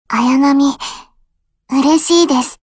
match-confirm.mp3